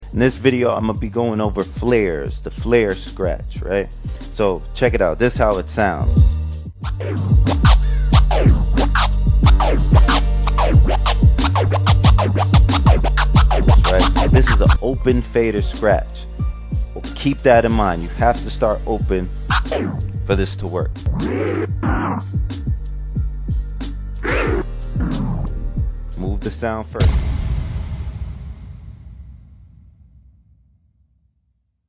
Flare Scratch Tutorial Out Now! sound effects free download